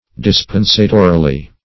Search Result for " dispensatorily" : The Collaborative International Dictionary of English v.0.48: Dispensatorily \Dis*pen"sa*to*ri*ly\, adv. In the way of dispensation; dispensatively.